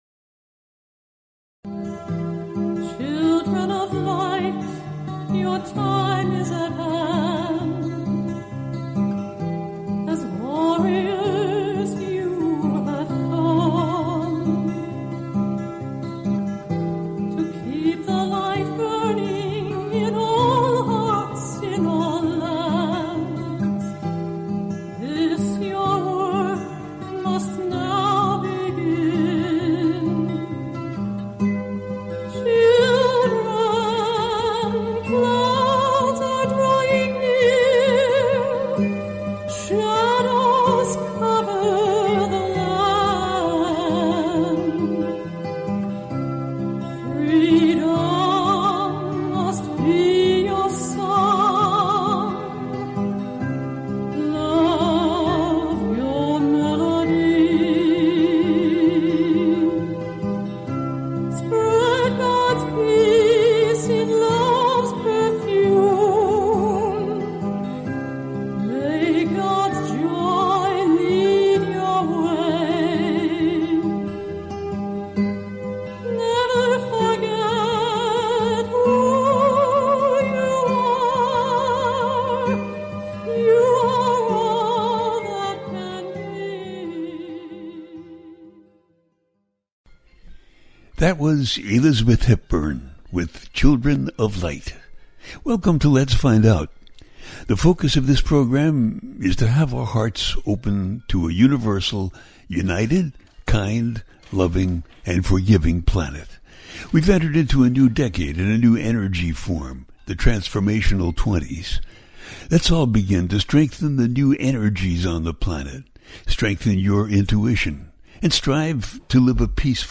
The New Era Begins-Aries Full Super Moon, A teaching show
The listener can call in to ask a question on the air.
Each show ends with a guided meditation.